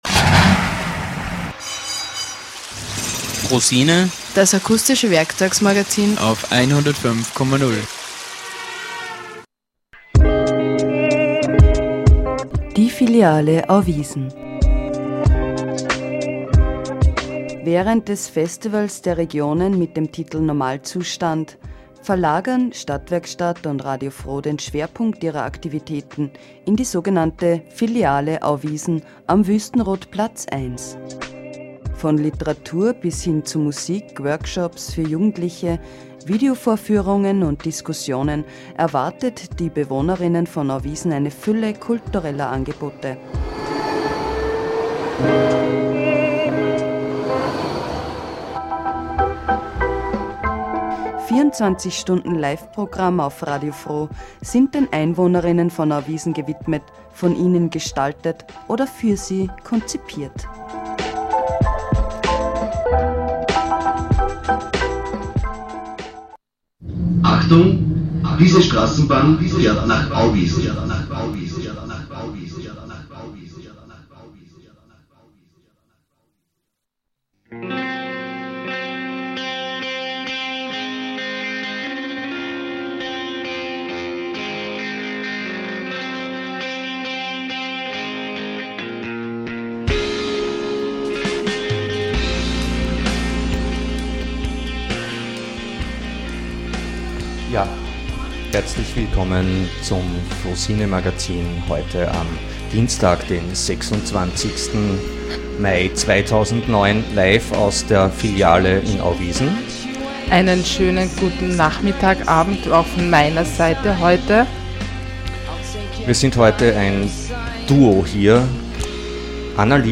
Live aus dem Studio in Auwiesen, quasi mit einem dezentralisierten Blick aufs Stadtgeschehen - ganz speziell zur Situation rund um den Ankauf der Tabakfabrik aka "Tschikbude".
Format: Stereo 44kHz